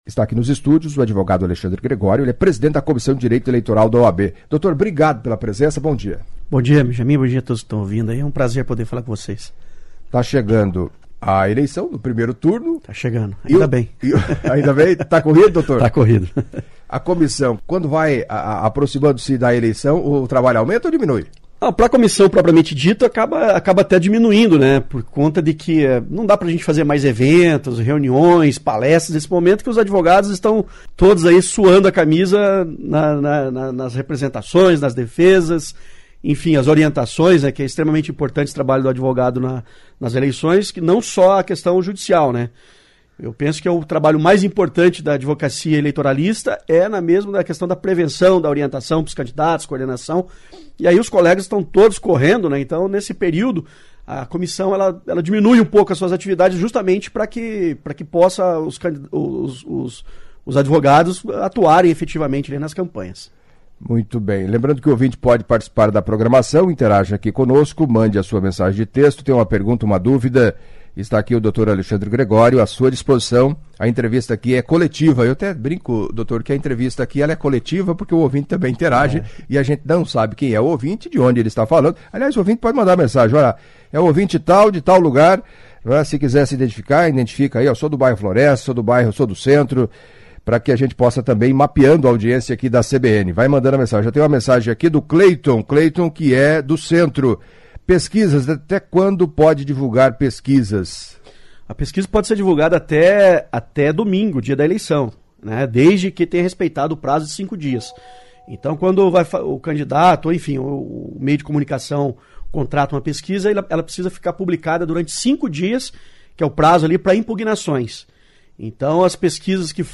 Em entrevista à CBN Cascavel nesta quarta-feria